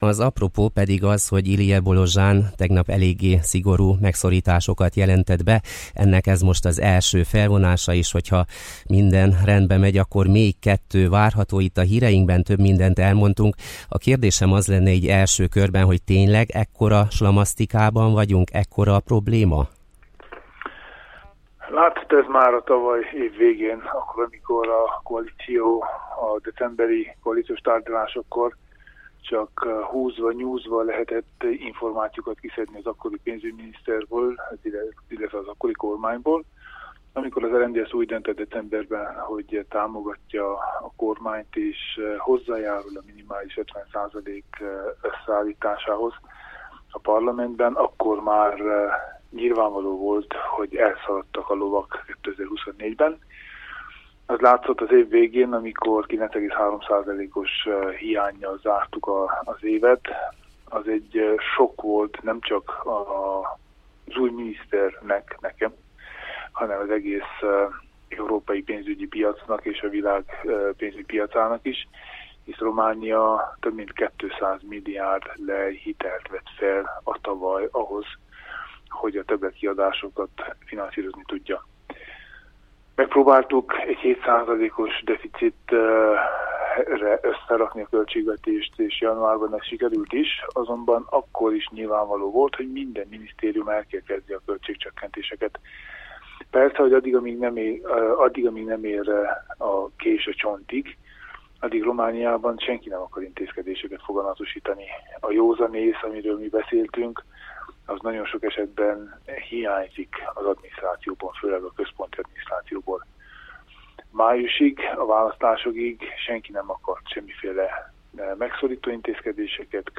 A kormányfőhelyettest kapcsoltuk ma reggel a tegnap bejelentett pénzügyi intézkedésekkel kapcsolatban.